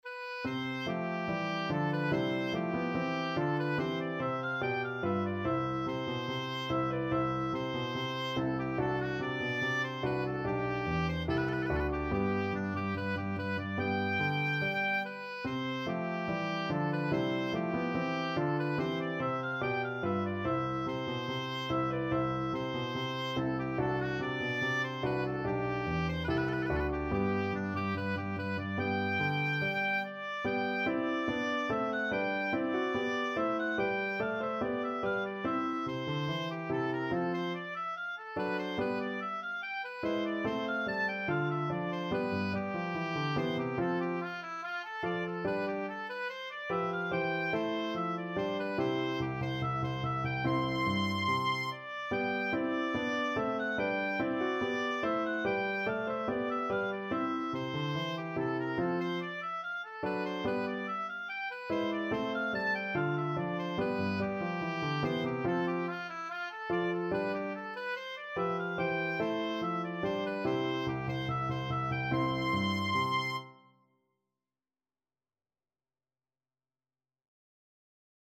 2/2 (View more 2/2 Music)
Animato = 144
Classical (View more Classical Oboe Music)